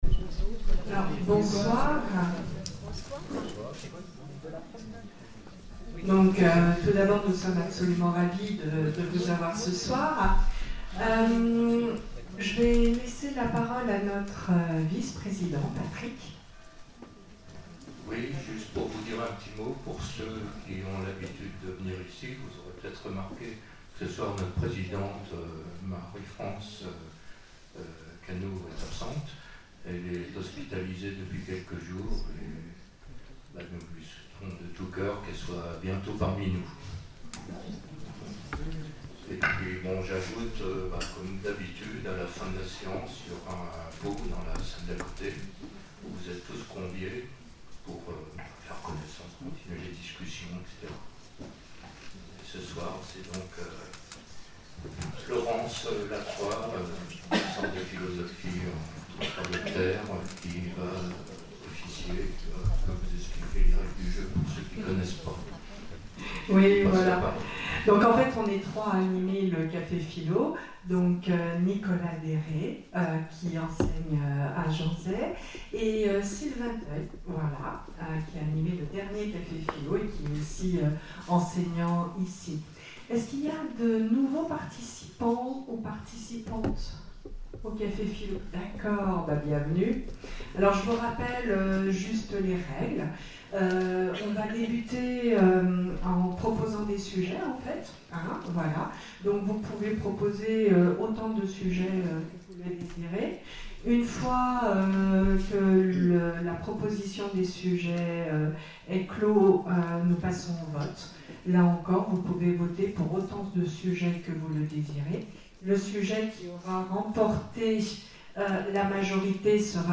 Conférences et cafés-philo, Orléans
CAFÉ-PHILO PHILOMANIA Le couple a-t-il de l’avenir ?